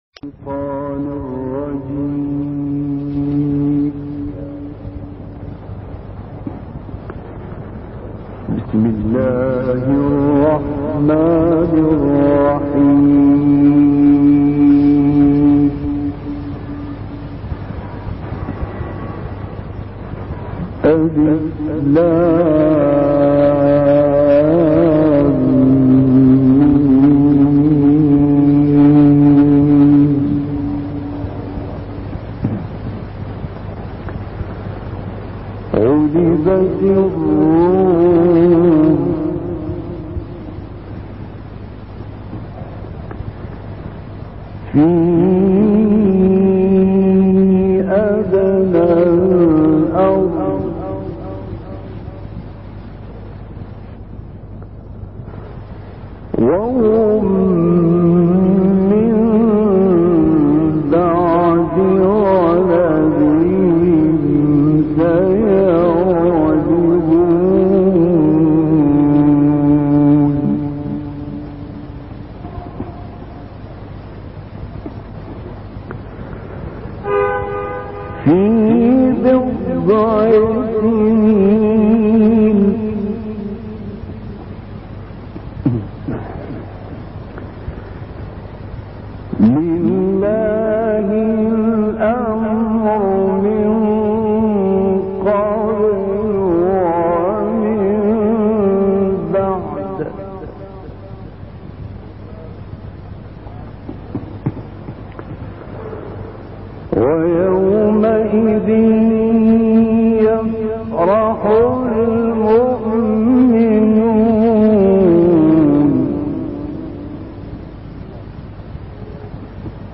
گروه شبکه اجتماعی: تلاوت آیاتی از سوره مبارکه روم با صوت شیخ راغب غلوش منتشر شد.